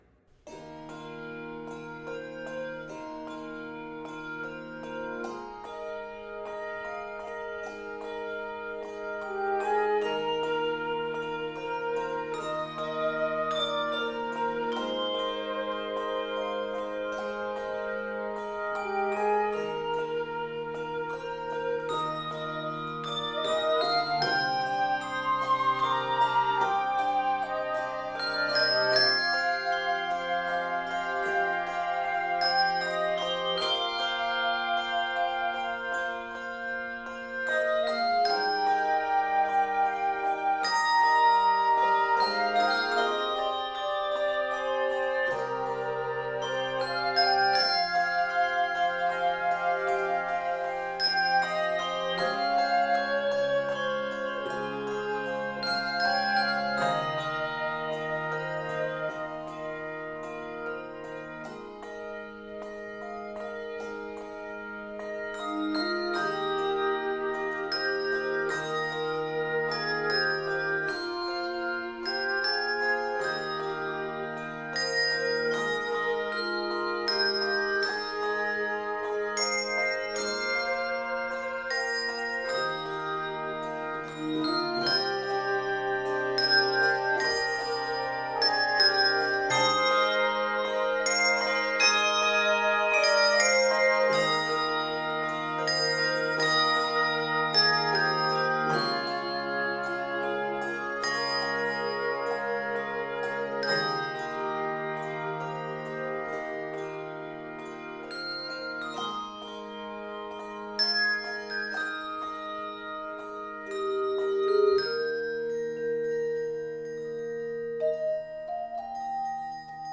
Key of Eb Major.
Octaves: 3-6